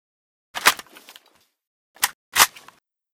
saiga_reload.ogg